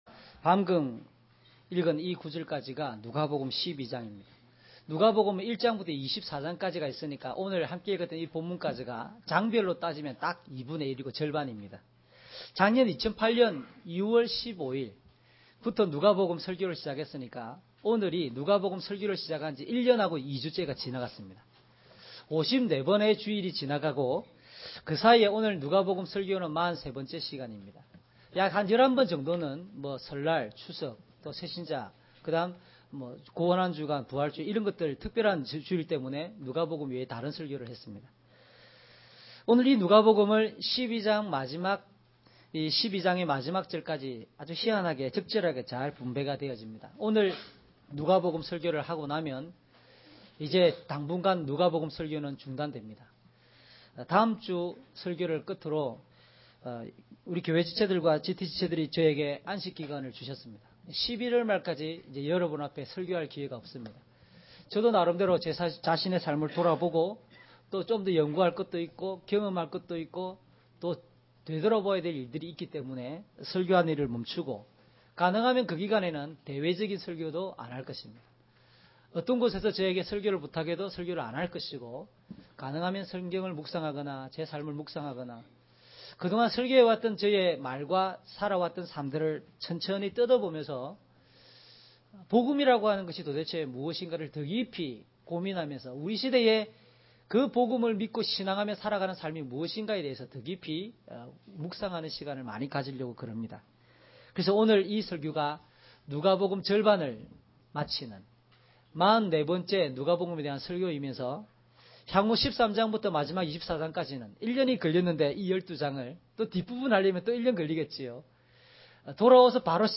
주일설교 - 09년 06월 28일 "구별된 삶을 향한 거룩한 용기를 가집시다."